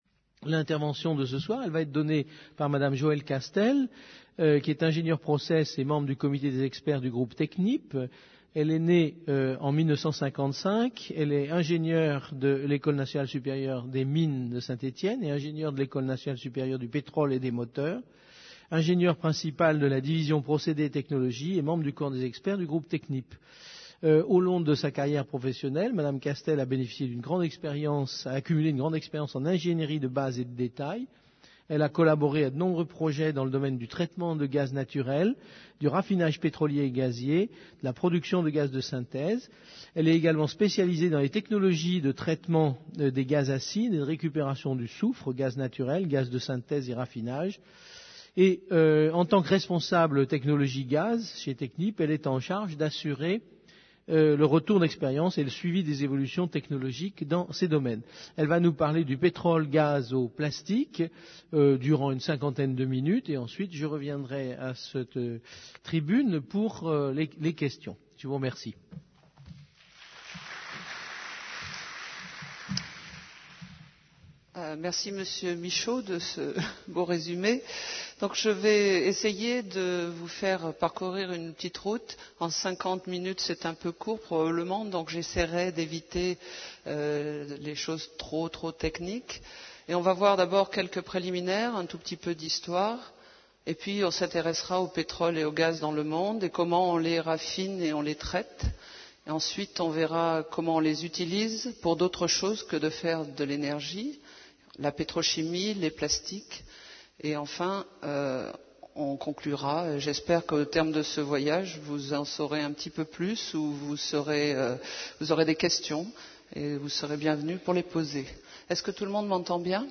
Un séminaire sur la question de la production et de la demande toujours croissante de matières premières et d'énergies dans le contexte actuel de crise climatique.